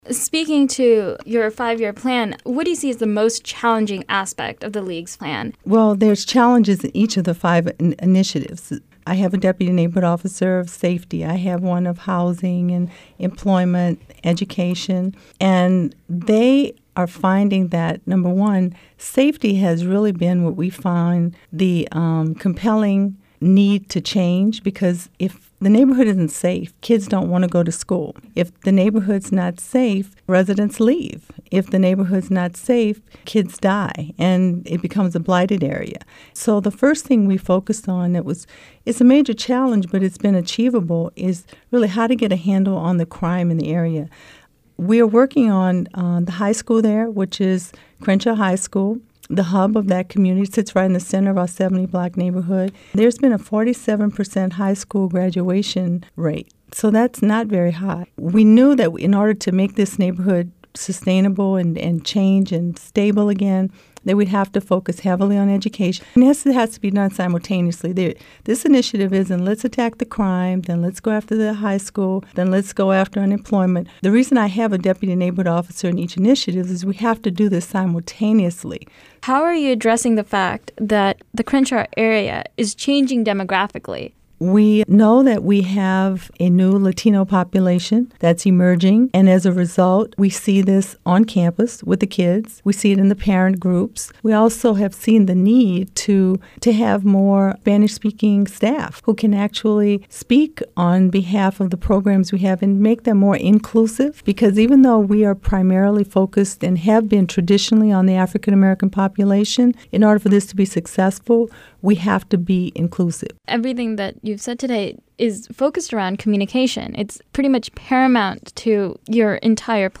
One-on-one